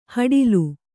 ♪ haḍilu